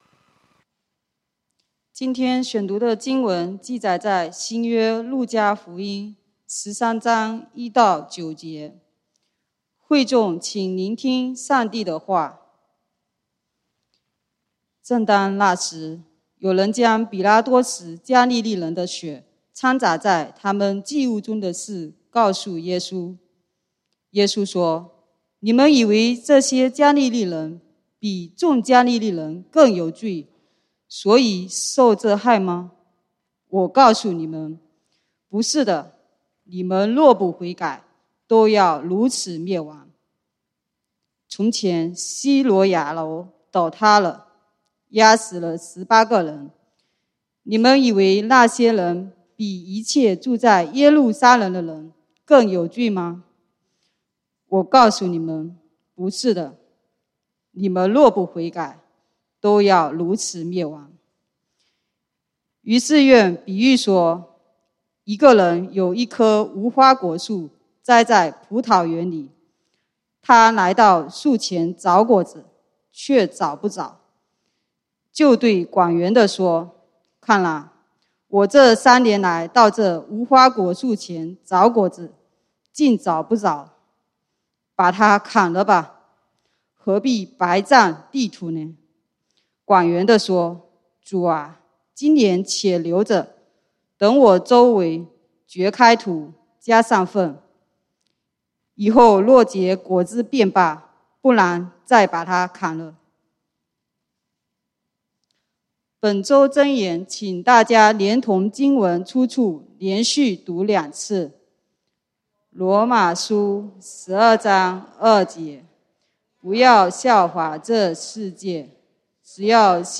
3/20/2022 講道經文：路加福音 Luke 13:1-9 本週箴言：羅馬書 Romans 12:2 不要效法這個世界，只要心意更新而變化，叫你們察驗何為上帝的善良、純全、可喜悅的旨意。